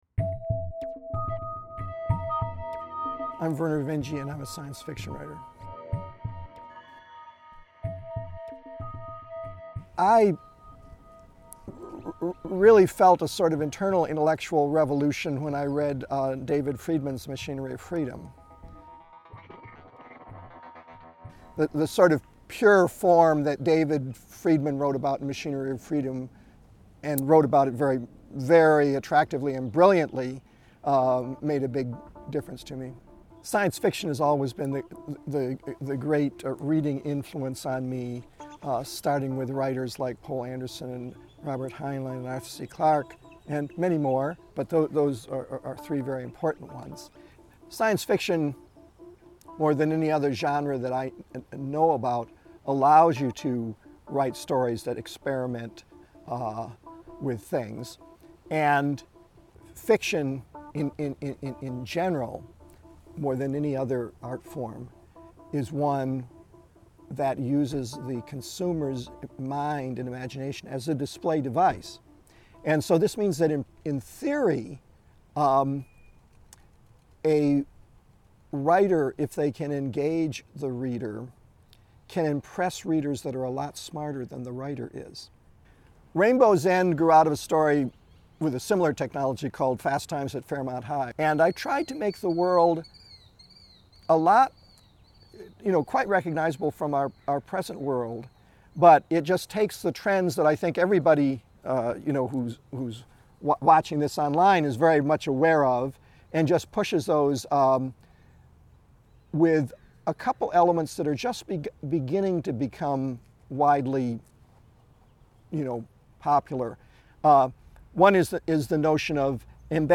Freedom, Science Fiction and the Singularity: A conversation with author Vernor Vinge